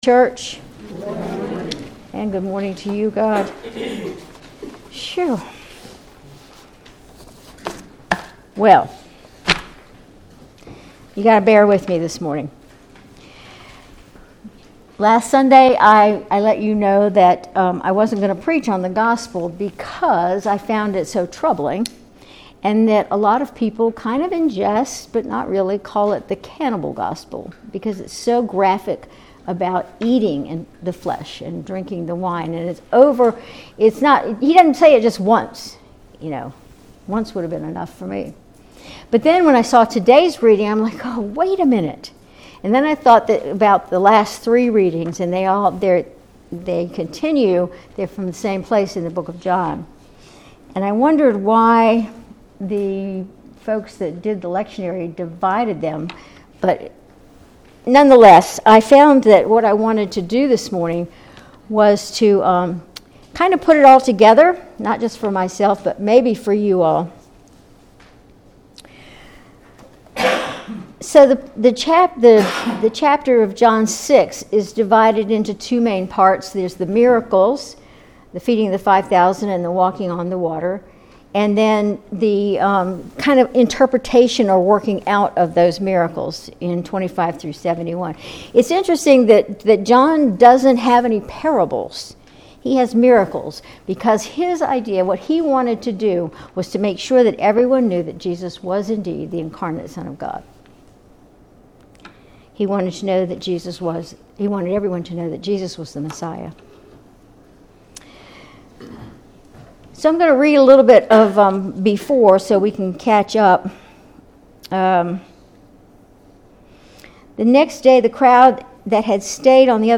Sermon August 25, 2024